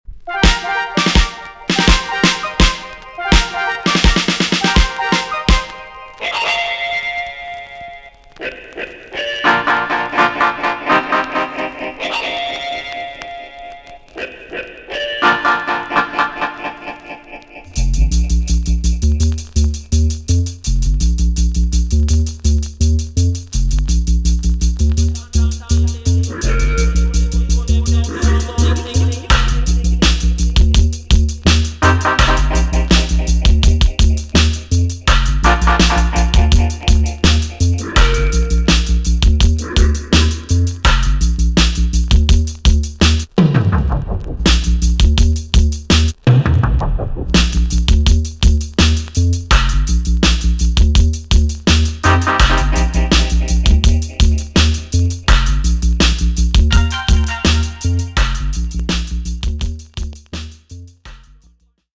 TOP >80'S 90'S DANCEHALL
B.SIDE Version
EX 音はキレイです。